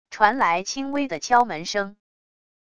传来轻微的敲门声wav音频